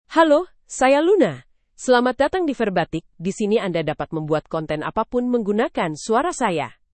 LunaFemale Indonesian AI voice
Luna is a female AI voice for Indonesian (Indonesia).
Voice sample
Listen to Luna's female Indonesian voice.
Female
Luna delivers clear pronunciation with authentic Indonesia Indonesian intonation, making your content sound professionally produced.